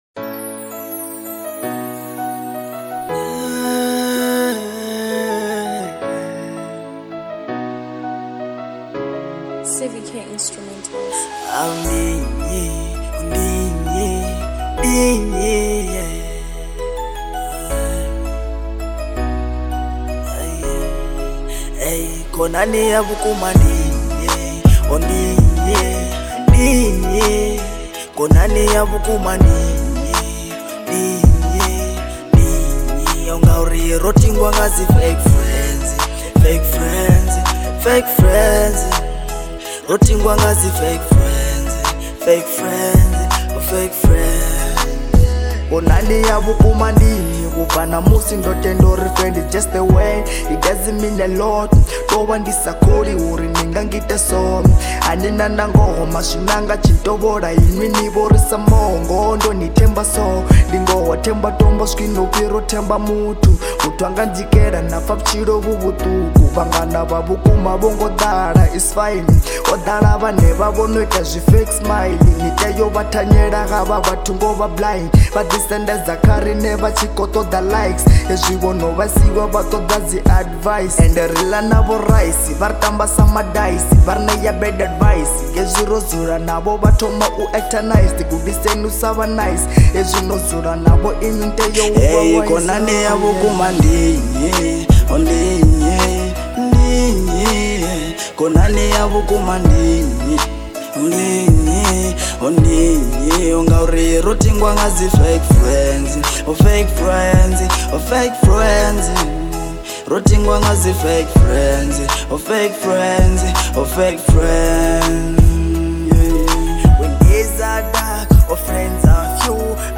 03:36 Genre : Venrap Size